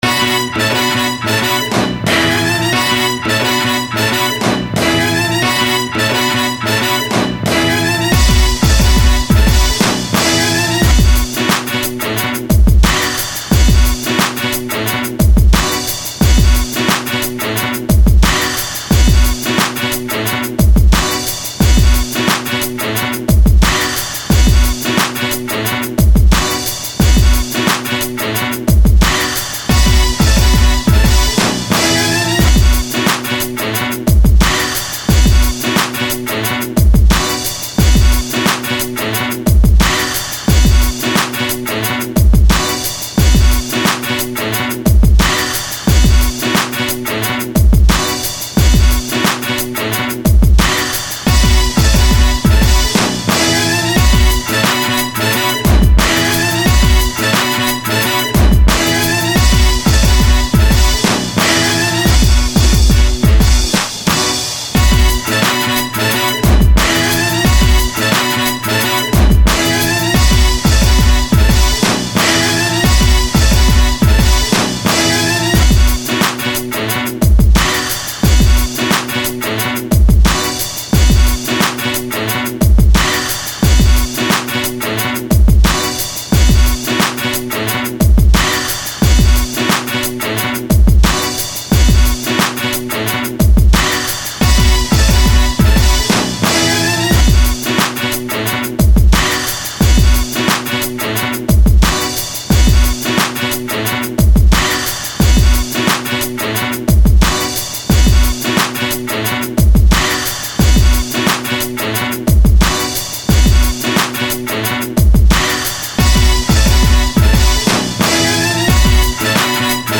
Inst./HipHop